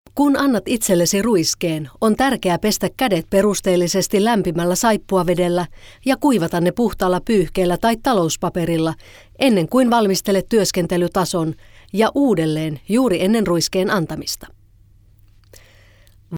Sprecherin finnisch für TV / Rundfunk / Industrie / Werbung.
Kein Dialekt
Sprechprobe: Werbung (Muttersprache):
Professionell female finish voice over artist.